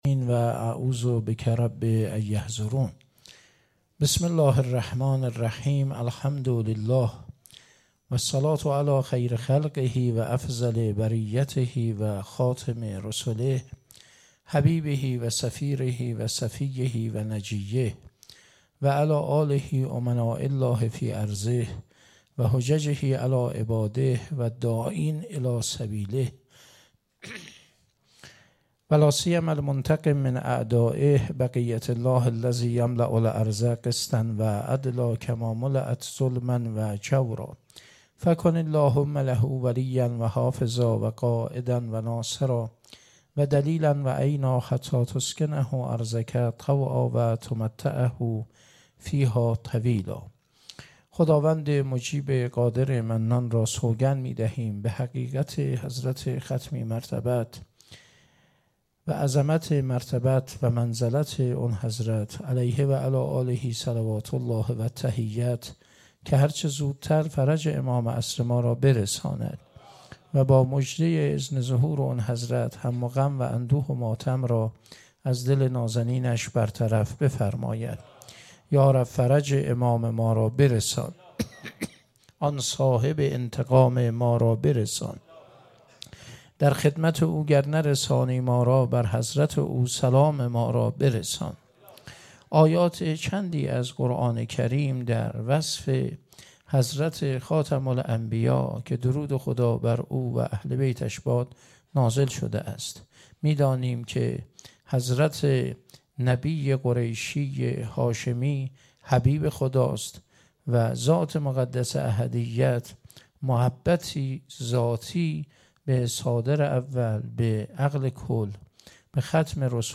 شب دوم - حسینیه ثامن
سخنرانی